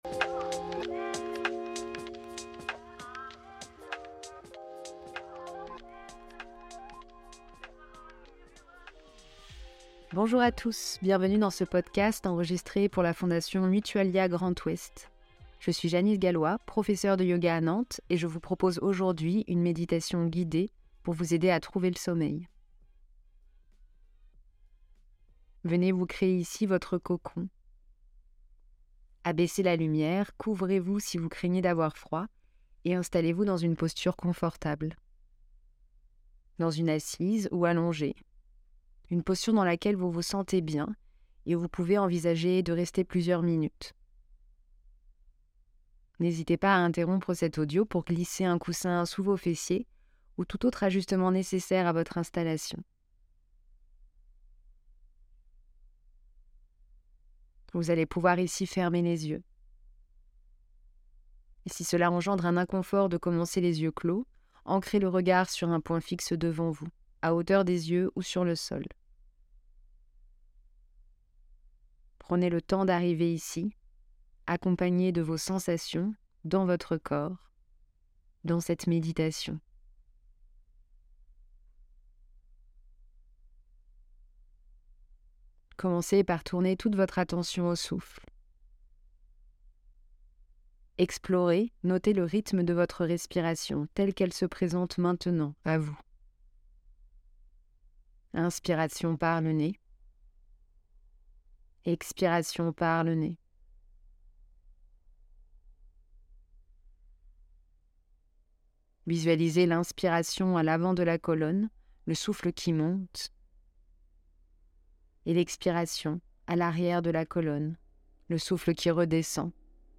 Méditation guidée pour aider à trouver le sommeil